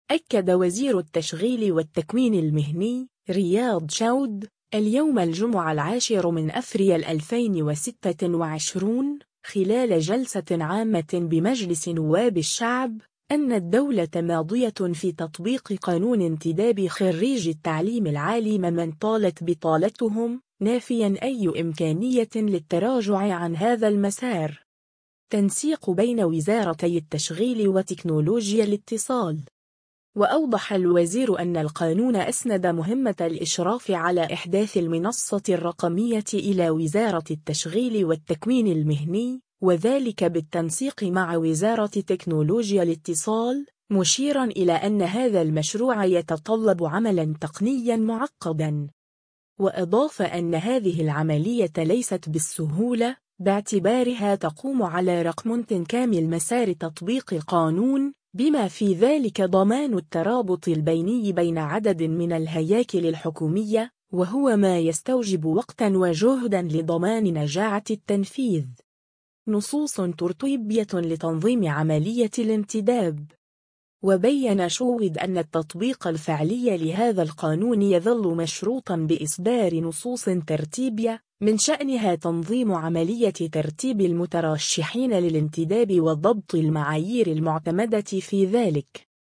أكد وزير التشغيل والتكوين المهني، رياض شوّد، اليوم الجمعة 10 أفريل 2026، خلال جلسة عامة بمجلس نواب الشعب، أن الدولة ماضية في تطبيق قانون انتداب خريجي التعليم العالي ممن طالت بطالتهم، نافيا أي إمكانية للتراجع عن هذا المسار.